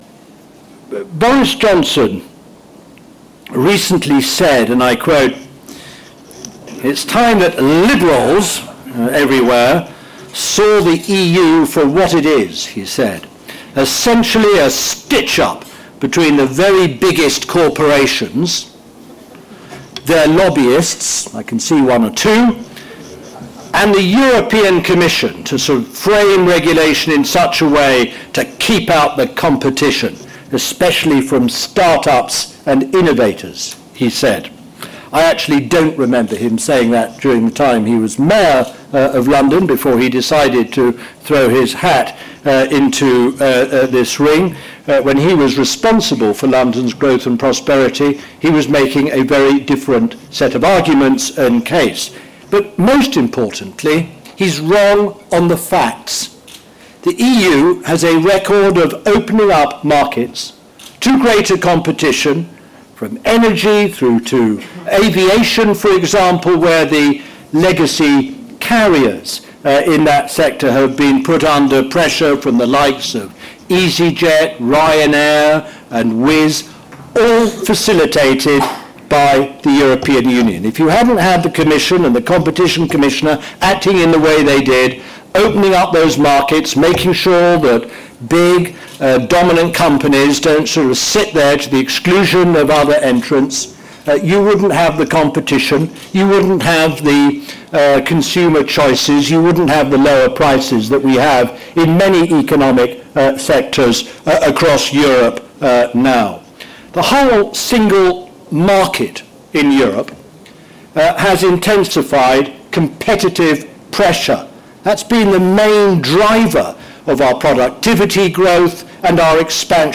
At our London Summer Business Lunch, Lord Mandelson keynote speaker, Lord Mandelson, former European Commissioner for Trade and Secretary of State for Business, talked about the benefits of EU membership.